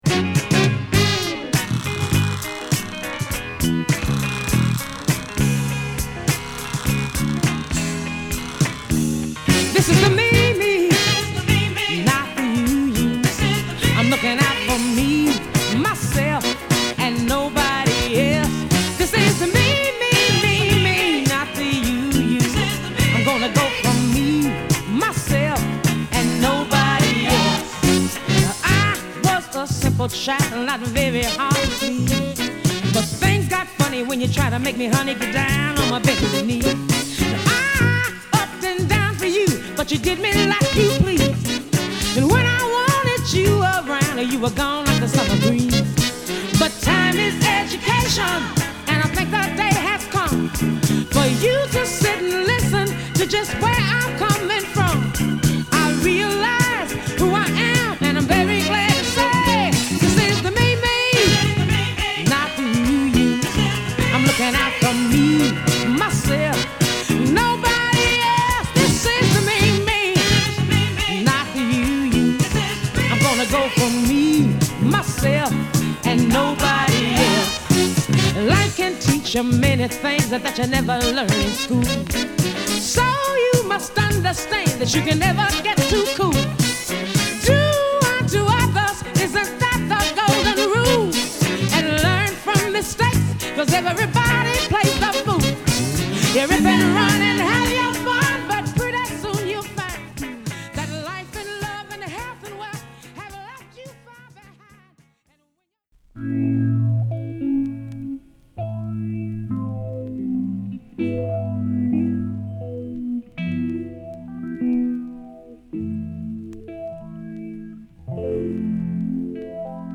ニュージャージー出身の巨漢オルガン奏者